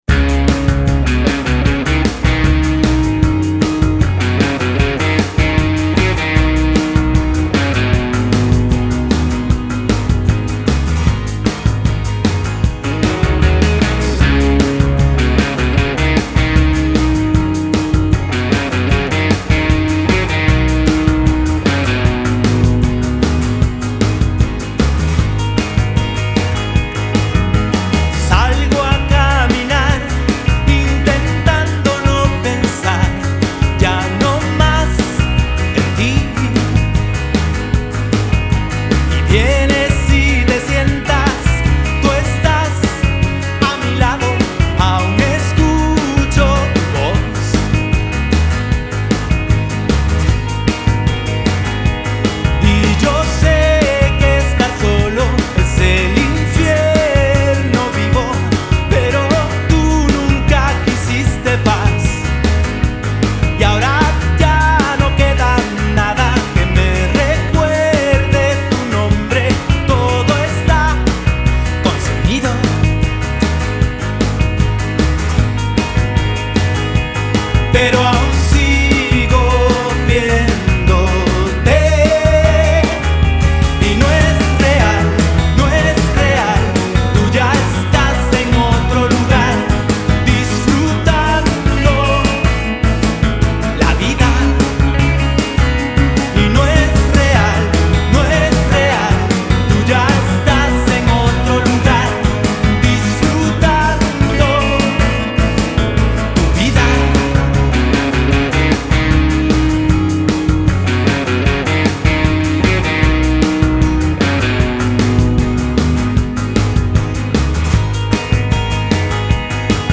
Productor Musical, Ingeniero de grabación & Guitarrista